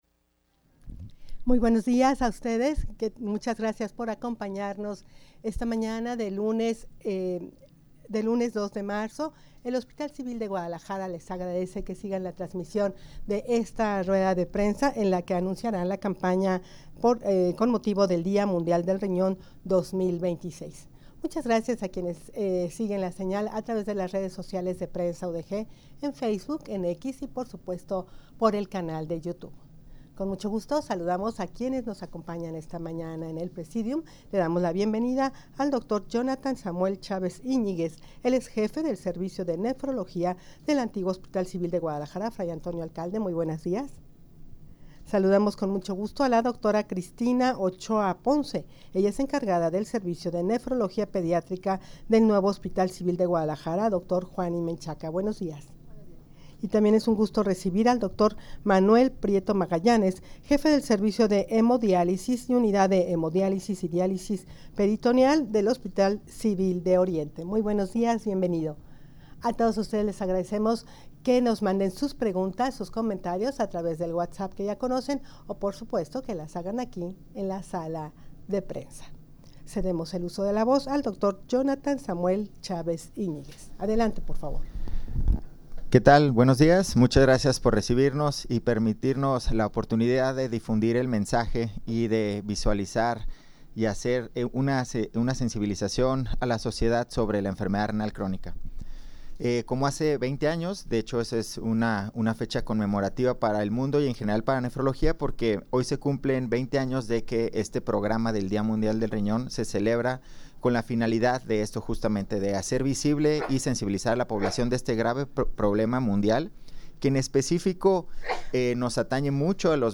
Audio de la Rueda de Prensa
rueda-de-prensa-para-anunciar-la-campana-dia-mundial-del-rinon-2026.mp3